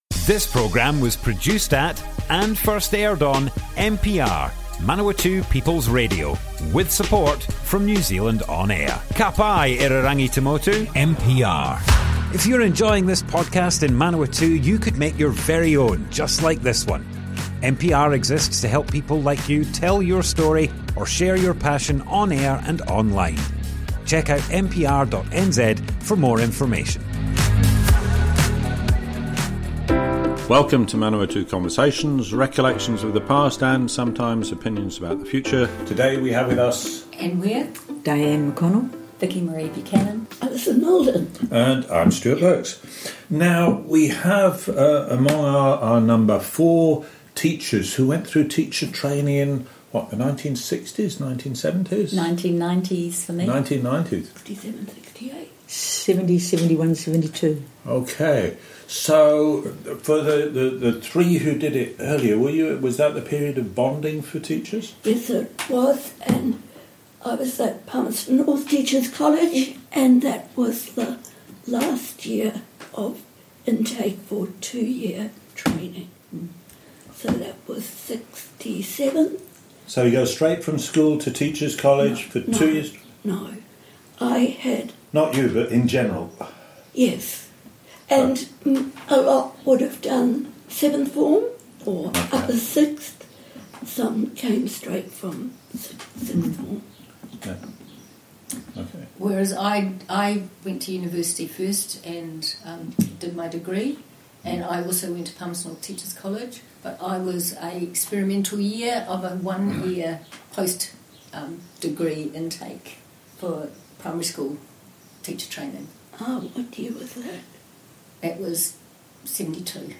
Group discussion, Teaching experiences during the late 1960s and early 1970s - Manawatu Conversations - Manawatū Heritage
00:00 of 00:00 Add to a set Other Sets Description Comments Group discussion, Teaching experiences during the late 1960s and early 1970s - Manawatu Conversations More Info → Description Broadcast on Manawatu People's Radio, 21st January 2025. All interviewees were teachers between 1967 and 1990s.
oral history